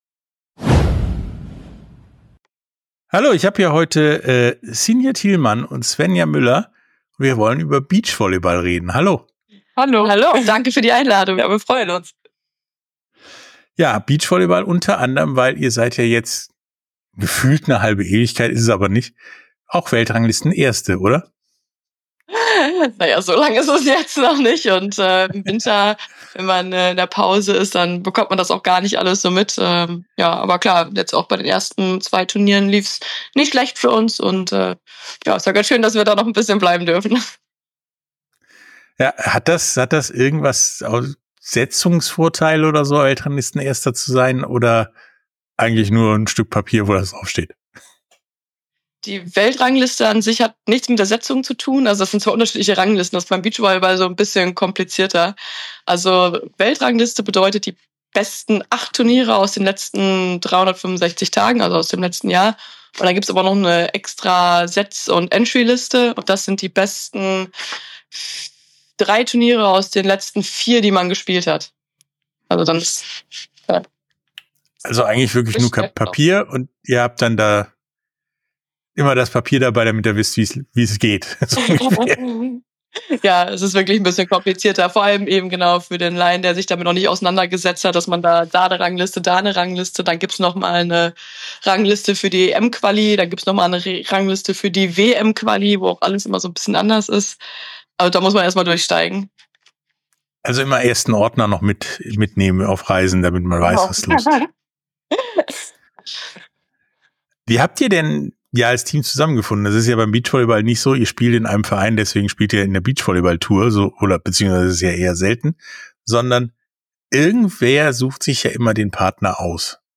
Interview komplett